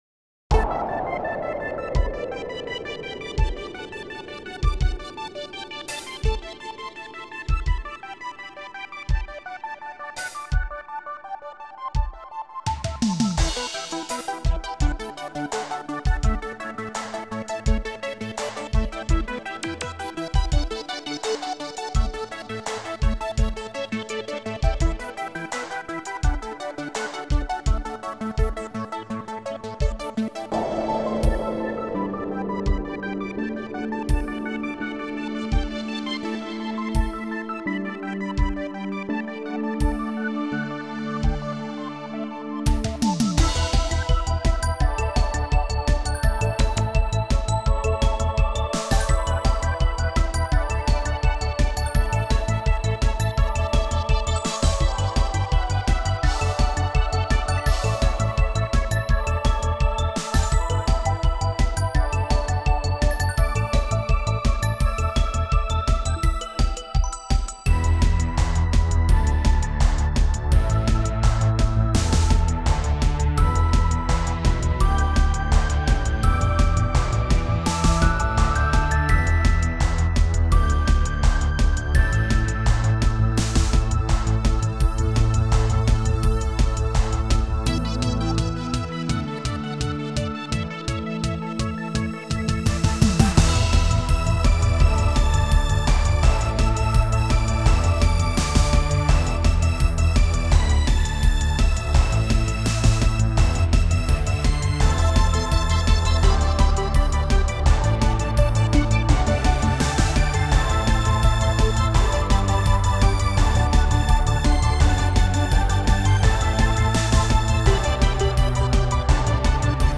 Буду рад замечаниям и комментариям по мастерингу, сведению, частотному спектру (да, я знаю, что верхов мало), стерео-разносу, и о том, как это все поправить.